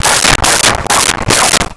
Dog Barking 03
Dog Barking 03.wav